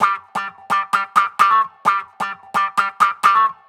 Electric Guitar 05.wav